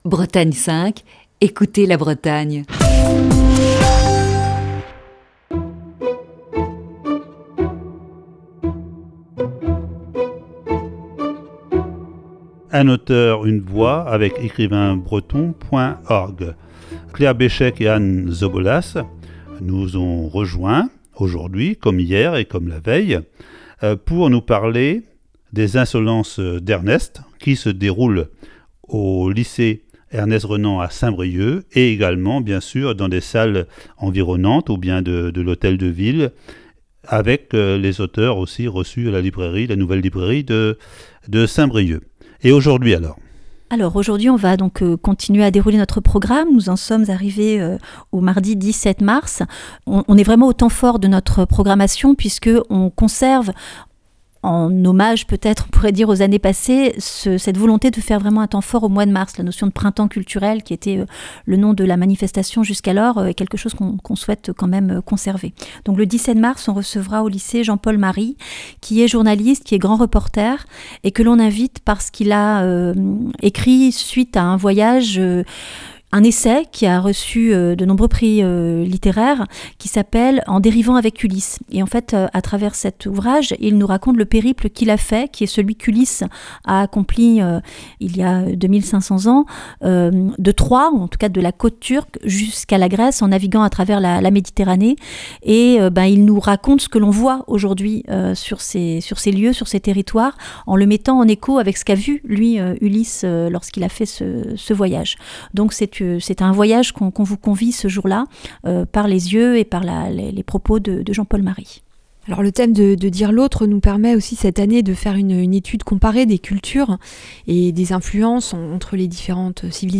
Voici ce vendredi la cinquième partie de cet entretien.